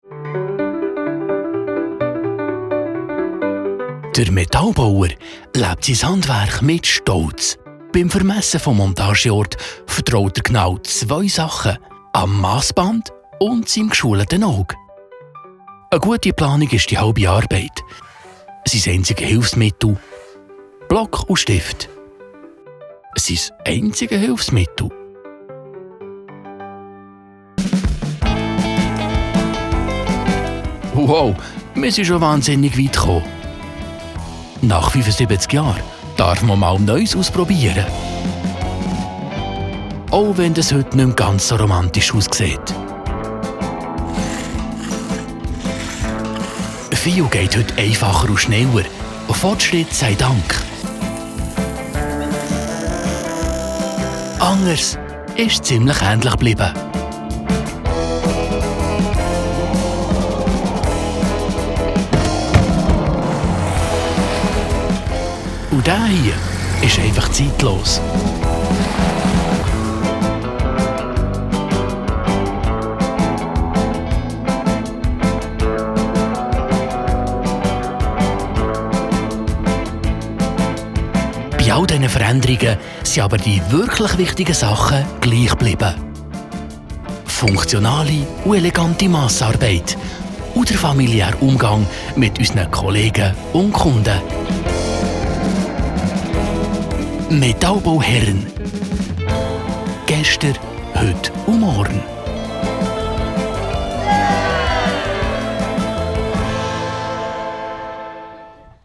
OFF-Voice Schweizerdeutsch (BE) Hörprobe 3